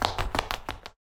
Sfx Player Enter Door Footsteps Sound Effect
sfx-player-enter-door-footsteps.mp3